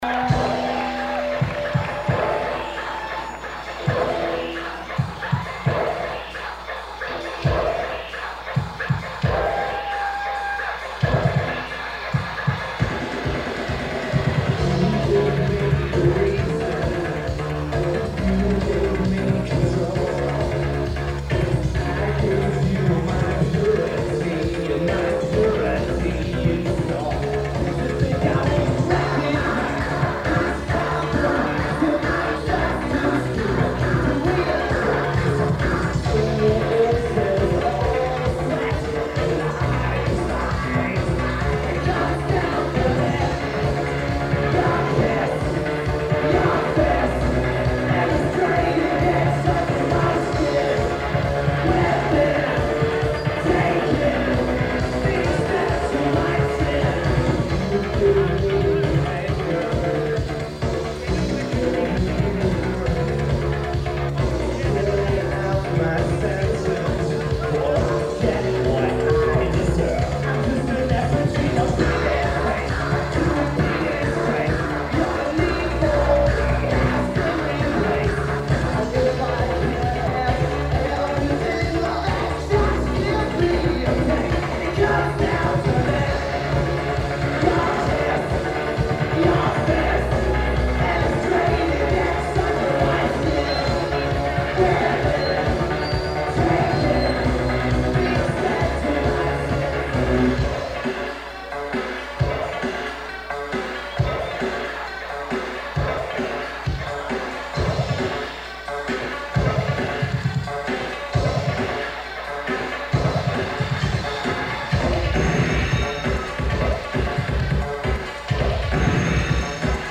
Citi Club
Lineage: Audio - AUD (Unknown Mic + Sony WM-D6)